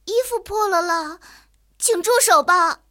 野牛中破语音.OGG